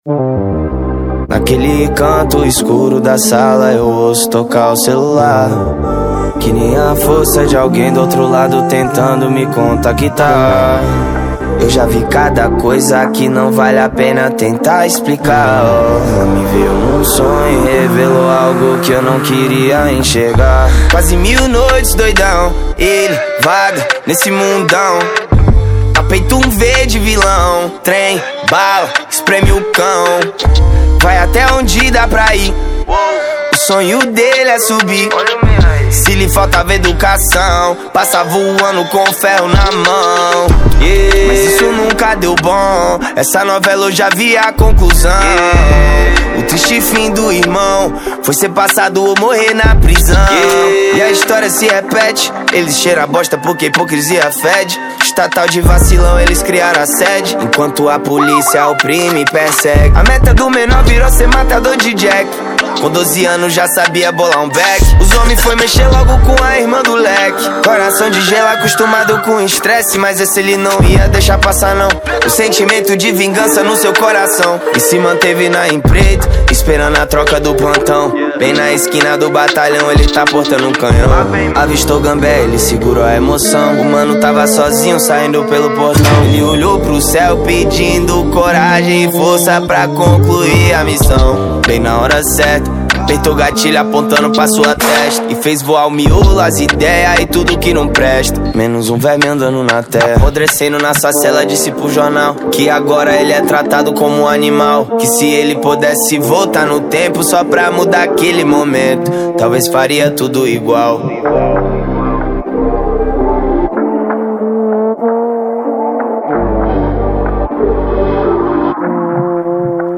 2025-02-15 20:19:06 Gênero: Trap Views